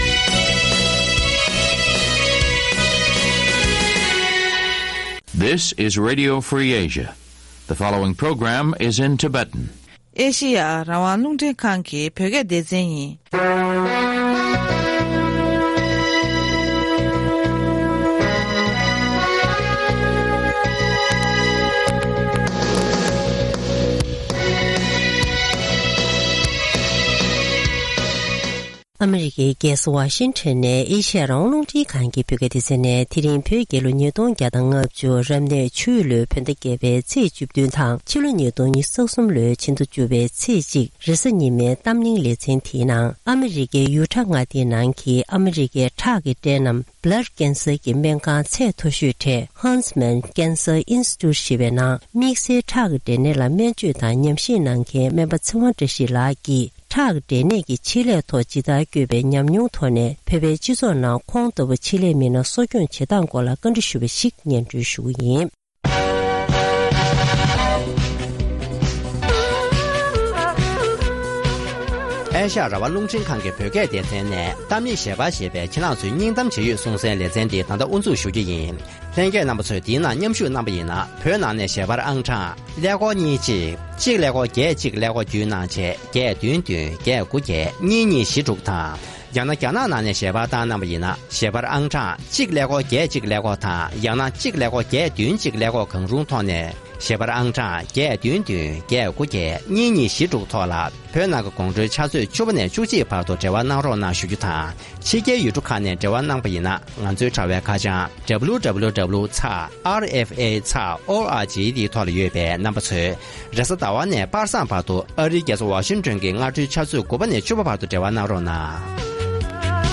ཐེངས་འདིའི་གཏམ་གླེང་གི་ལེ་ཚན་ནང་།